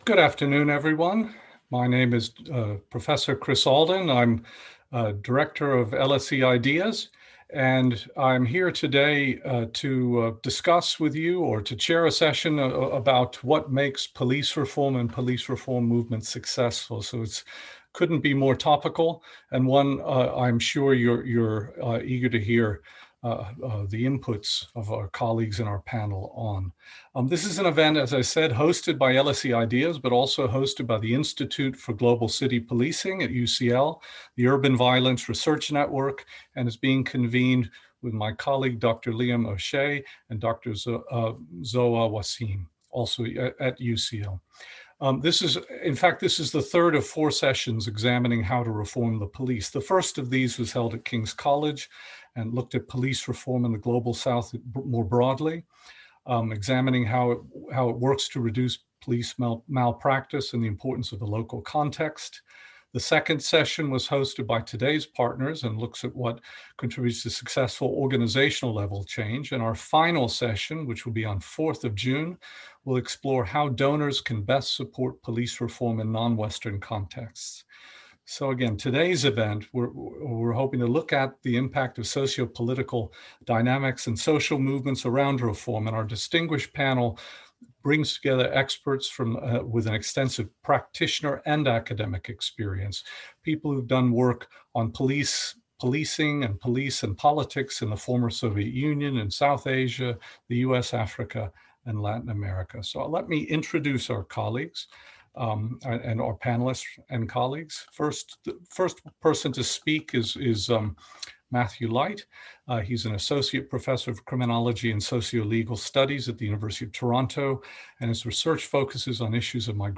Our panel draw on their research and campaigning experience to explore what makes a successful police reform movement.